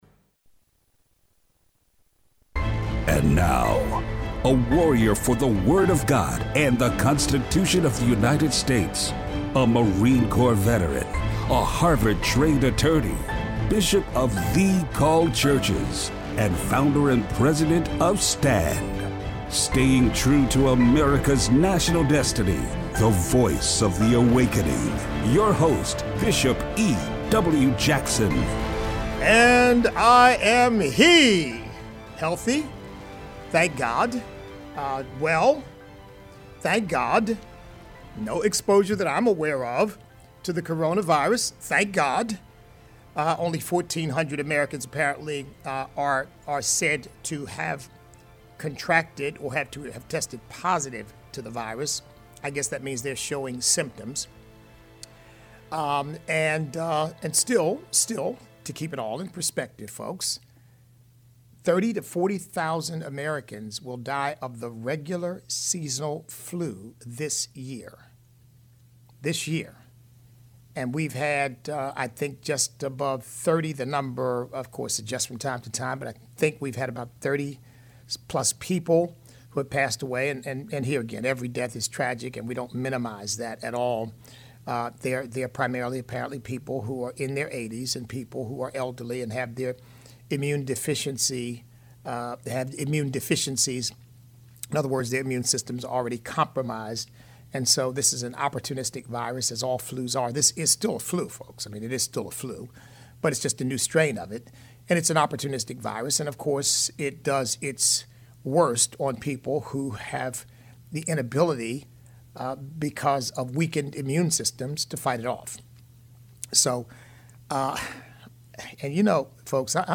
A Christian Perspective On The Coronavirus. Listener call-in.